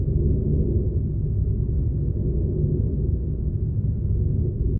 scpcb-godot/SFX/Ambient/Room ambience/pulsing.ogg at ae8b17a347ad13429a7ec732a30ac718cea951e4
pulsing.ogg